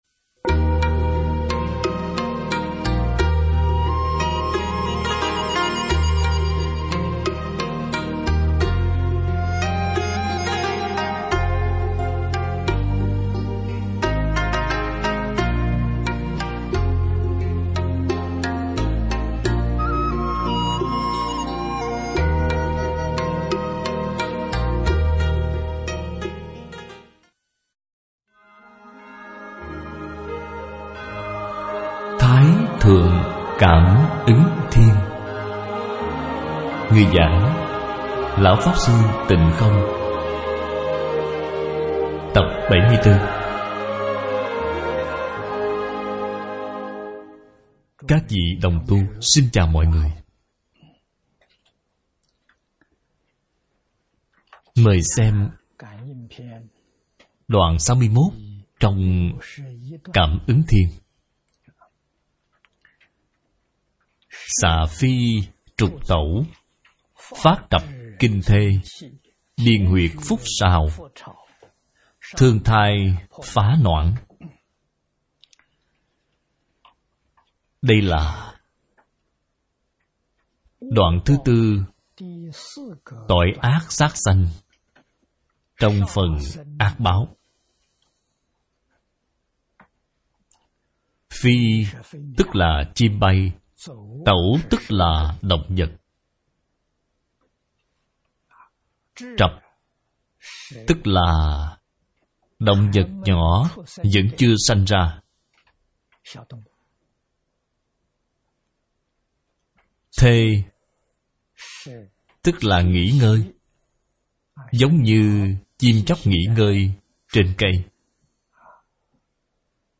Thái Thượng Cảm Ứng Thiên - Tập 109 - Bài giảng Video